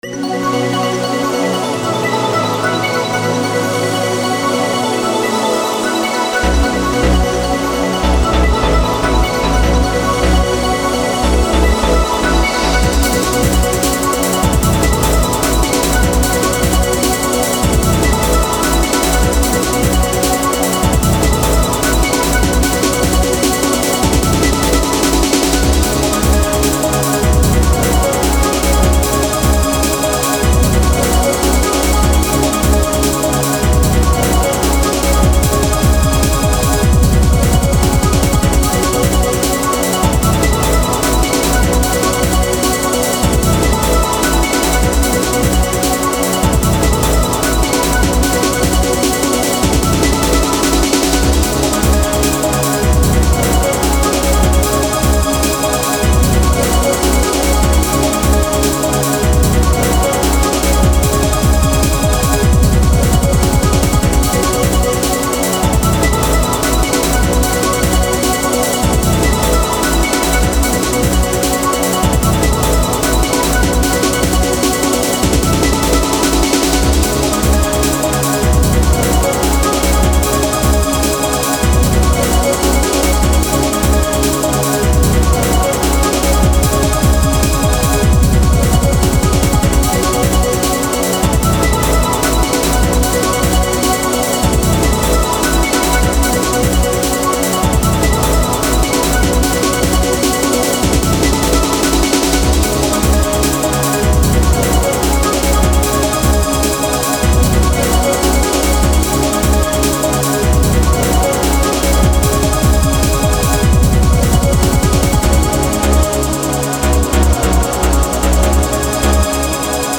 Dreamy, Nintendo Styled ARTCORE JUNGLE.
Music / Game Music